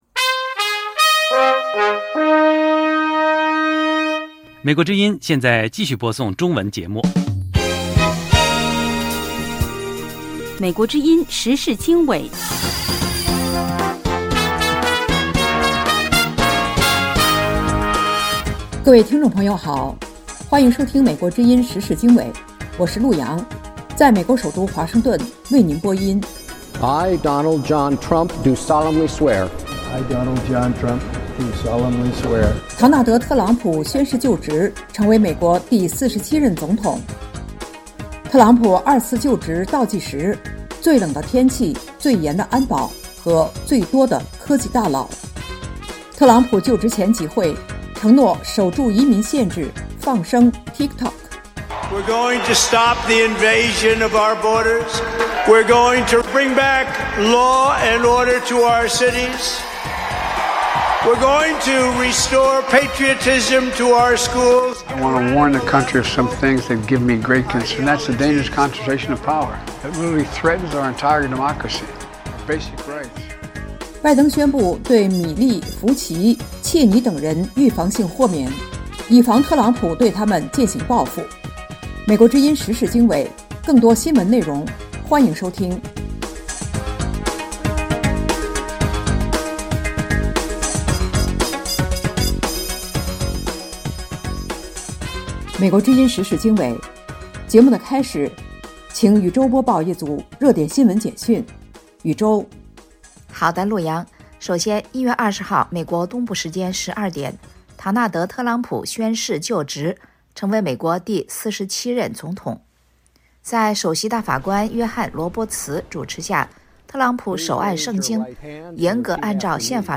美国之音中文广播《时事经纬》重点报道美国、世界和中国、香港、台湾的新闻大事，内容包括美国之音驻世界各地记者的报道，其中有中文部记者和特约记者的采访报道，背景报道、世界报章杂志文章介绍以及新闻评论等等。